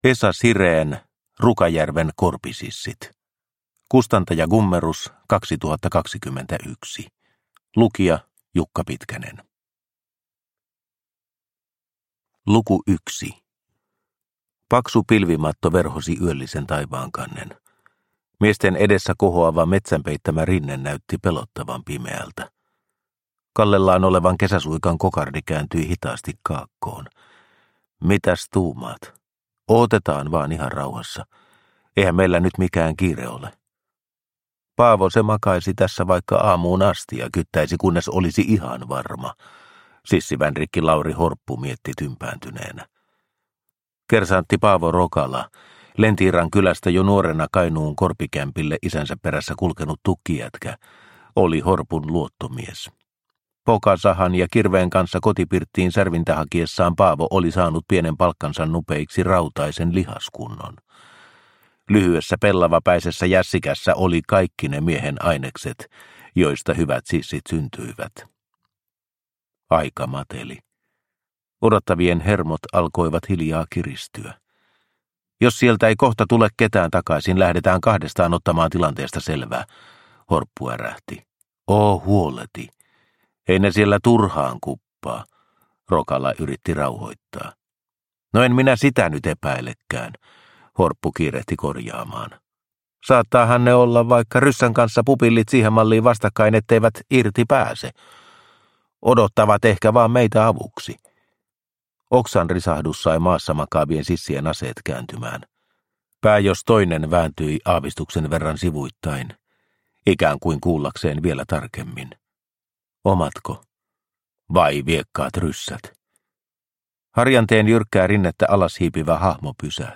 Rukajärven korpisissit – Ljudbok – Laddas ner